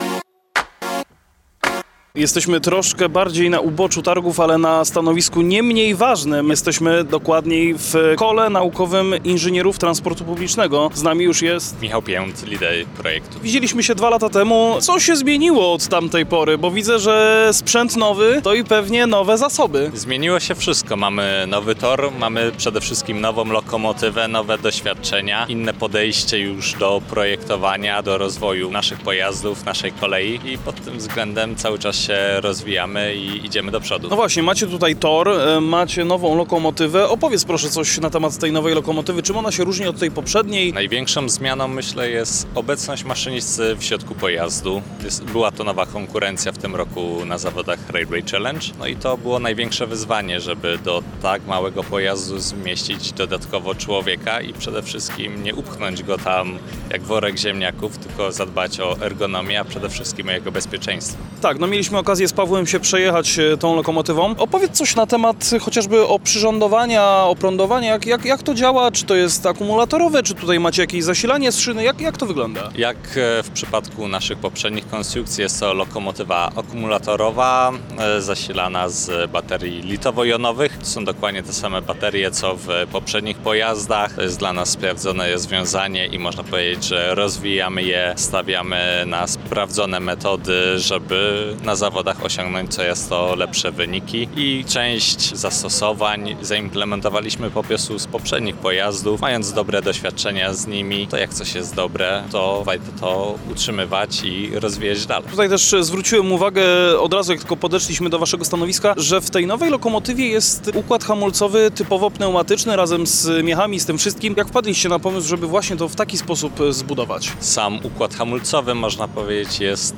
Zapraszamy Was do wysłuchania ostatniego wywiadu z serii materiałów nagranych podczas 16. Międzynarodowych Targów Kolejowych TRAKO 2025!